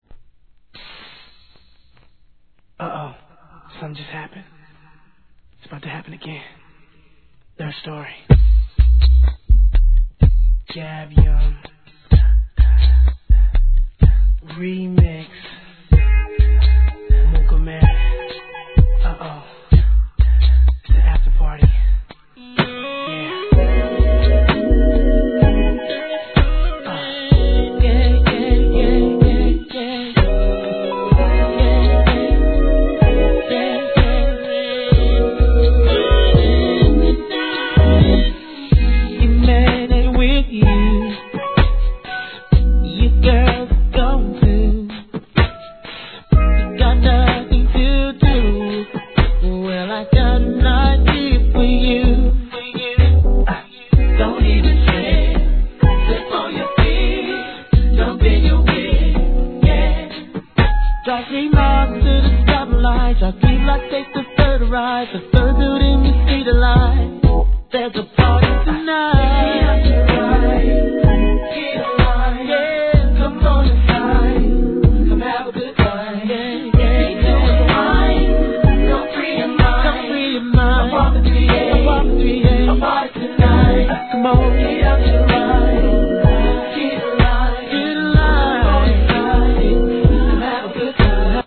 HIP HOP/R&B
のっけからヴォコーダーを効かせ、当時はまだ幼いながらも実力を見せ付けた本物のR&B!!